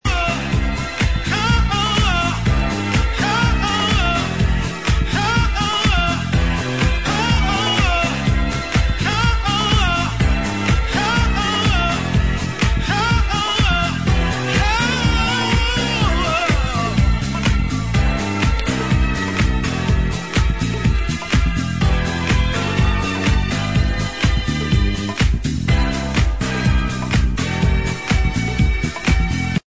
House Id